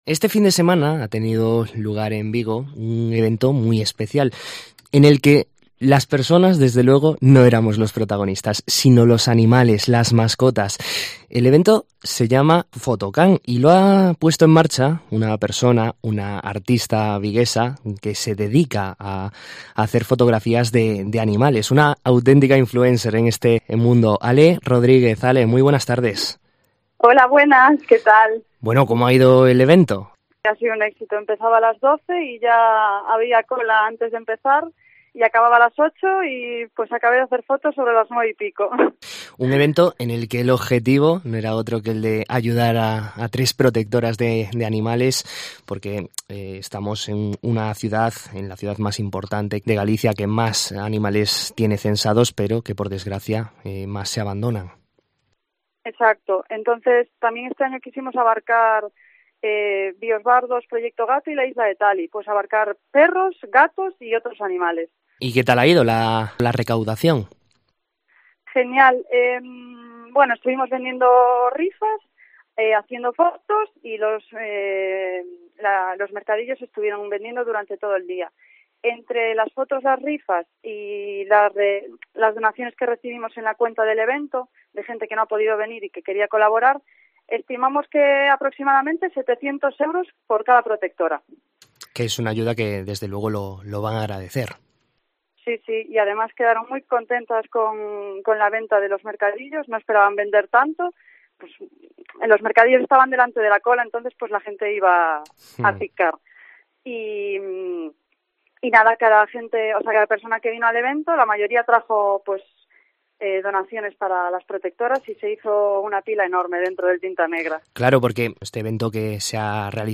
nos cuenta en los micrófonos de COPE Vigo cómo ha ido